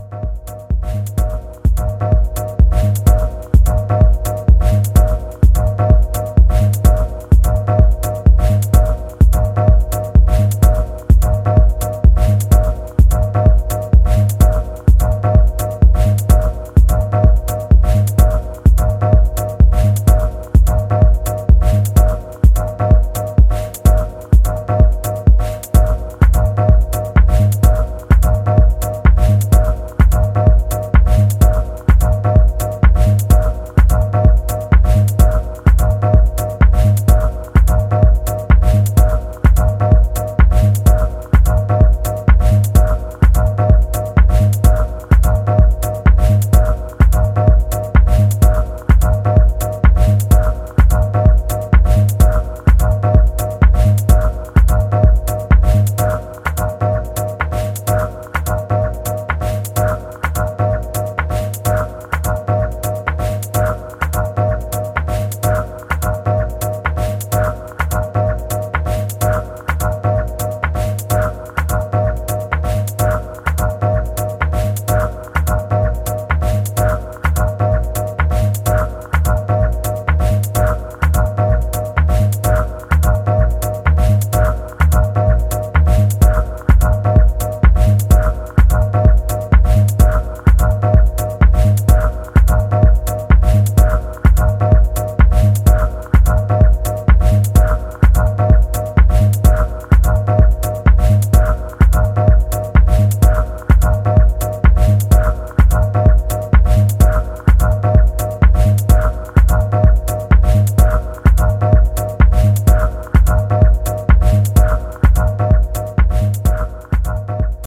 思わず腰が動く軽快で躍動感に満ちたボトムをコード・ショットがクールに刻む